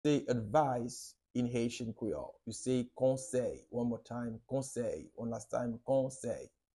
How to say “Advice” in Haitian Creole – “Konsèy” pronunciation by a native Haitian Teacher
“Konsèy” Pronunciation in Haitian Creole by a native Haitian can be heard in the audio here or in the video below:
How-to-say-Advice-in-Haitian-Creole-–-Konsey-pronunciation-by-a-native-Haitian-Teacher.mp3